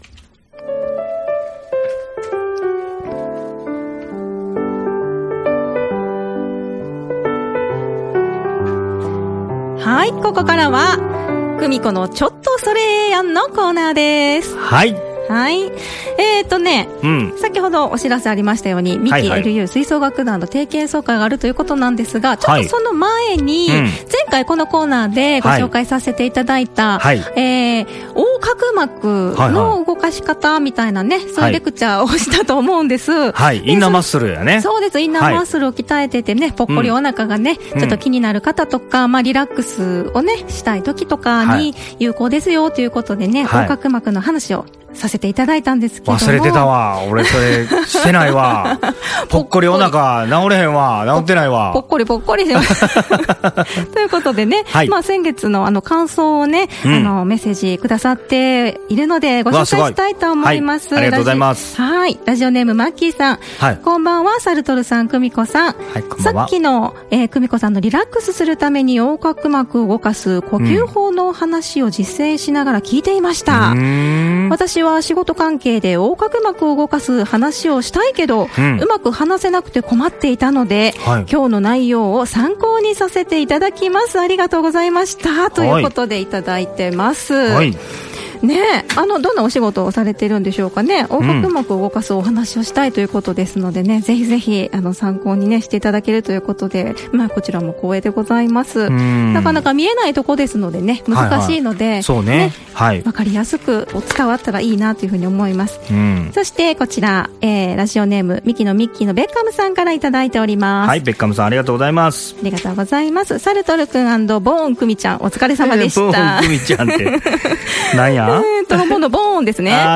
日時 6/22（日）14:00開演（13:30開場） 場所 三木市文化会館大ホール 熱く語ってくれました。